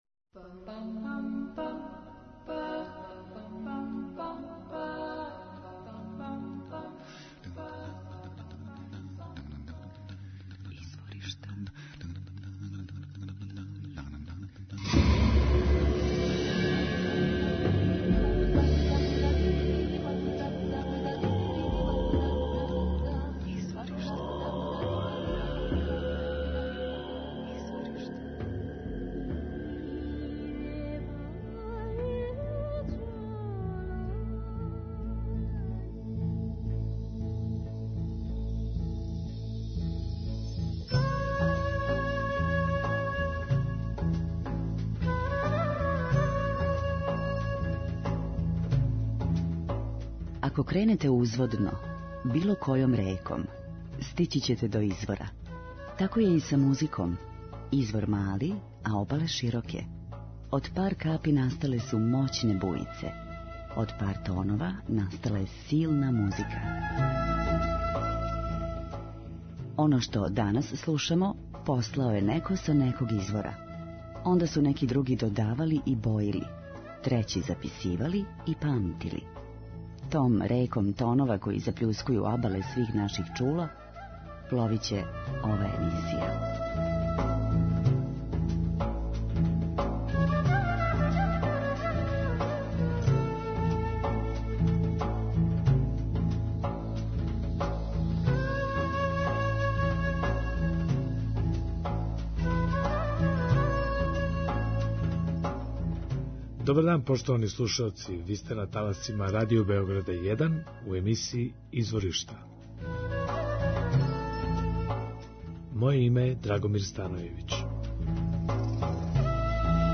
Лондонски танго оркестар слави нове звуке, боје и идеје из богатог репертоара старог и новог танга.
У ритму танга и нео-ребетика
Нео-Ребетико група Трио Текке избила је на сцену у последњих неколико година са својим експерименталним приступом 'грчком блузу'.